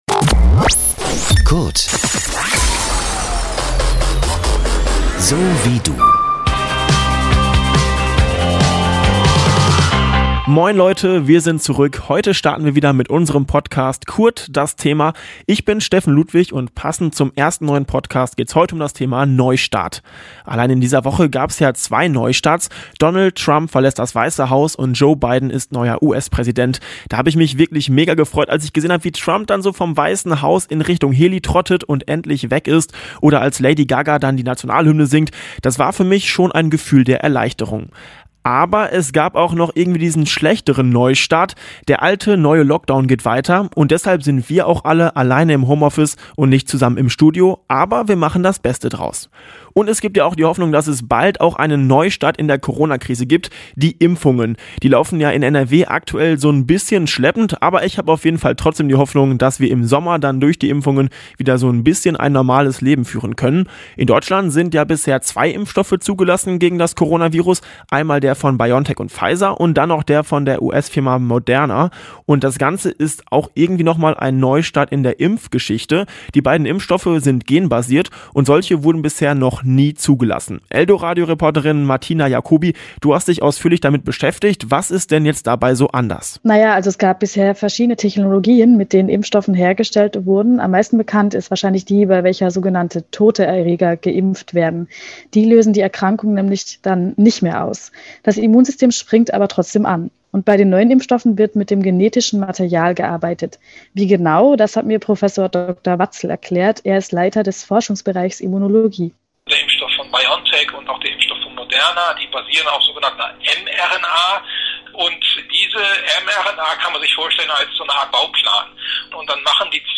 Deshalb sind wir auch alle alleine im Home Office und nicht zusammen im Studio. Aber wir machen das Beste draus und schauen auf Neustarts, die Hoffnung machen: Für's Klima und auch in Sachen Corona.